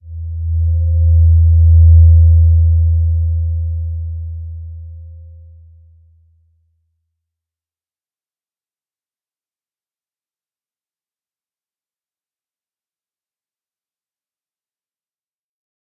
Slow-Distant-Chime-E2-p.wav